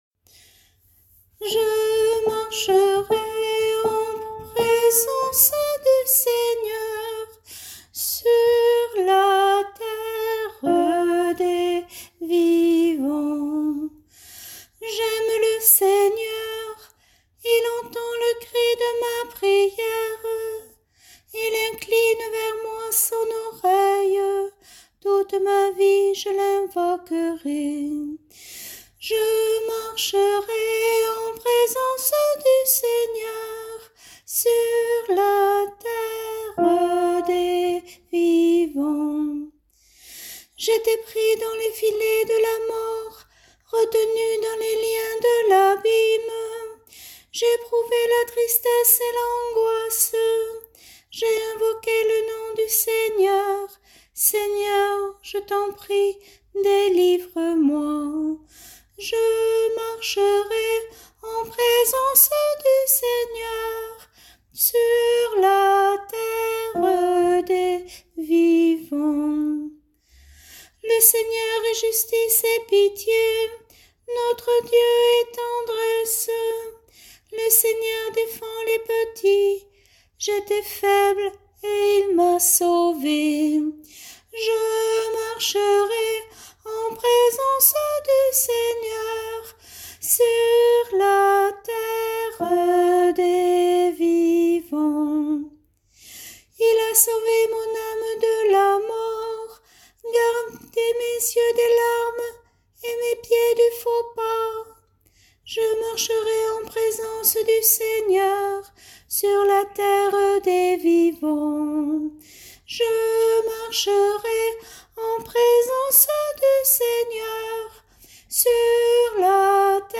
Chorale psaumes année B – Paroisse Aucamville Saint-Loup-Cammas